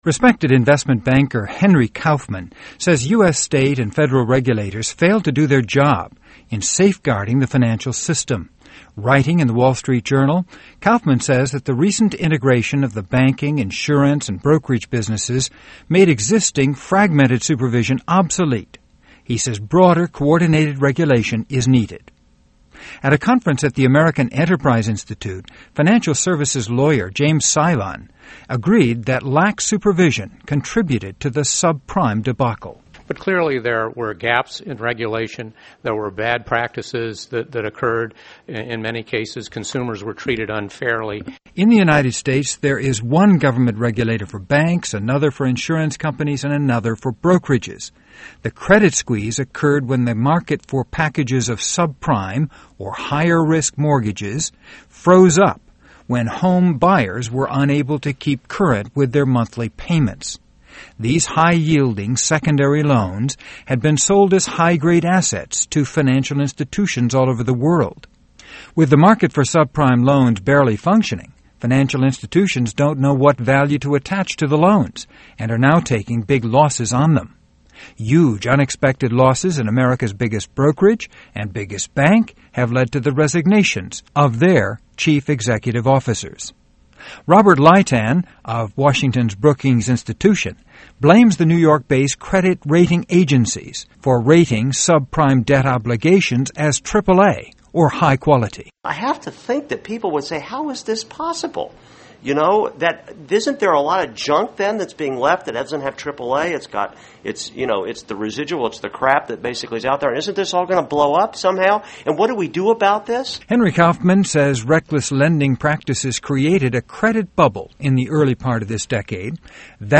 您现在的位置是：首页 > 英语听力 > VOA英语听力下载|VOA news > voa标准英语|美国之音常速英语下载|在线收听